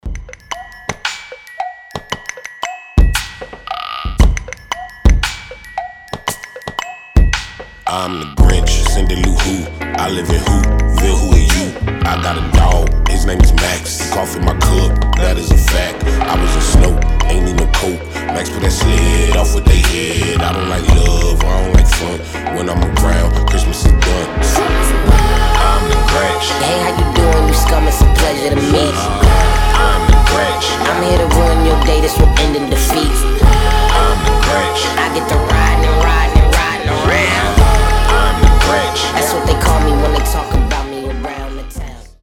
• Качество: 320, Stereo
мелодичные
детский голос
хор
рождественские